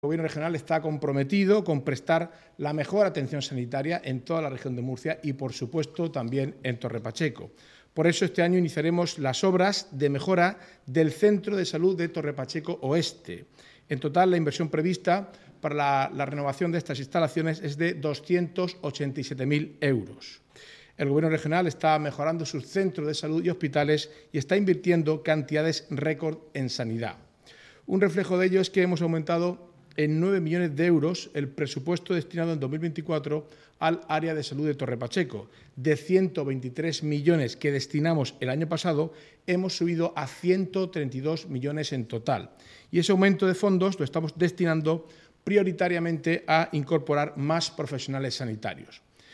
Declaraciones del consejero de Presidencia, Portavocía y Acción Exterior, Marcos Ortuño, sobre las inversiones en materia sanitaria que el Gobierno regional destina este año a Torre Pacheco. Ortuño compareció tras la reunión mantenida hoy en San Esteban entre el presidente de la Comunidad, Fernando López Miras, y el alcalde de Torre Pacheco, Pedro Ángel Roca